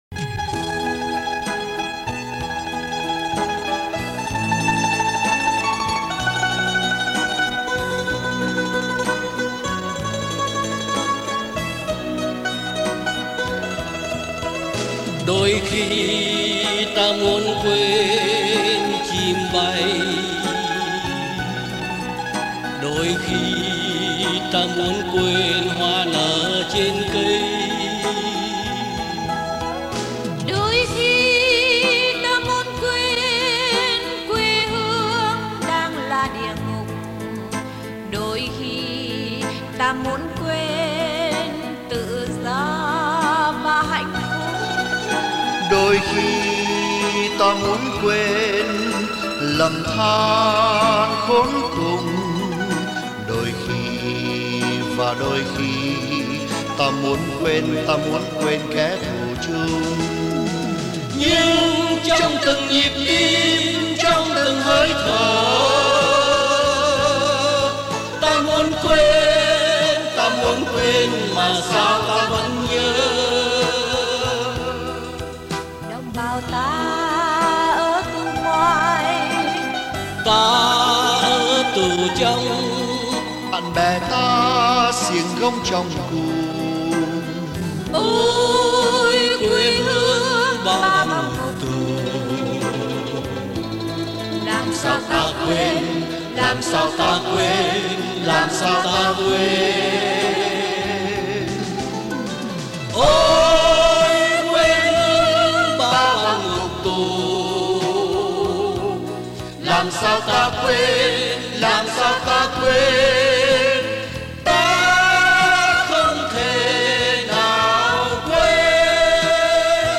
Về bài tù khúc “Quê hương ba vòng ngục tù”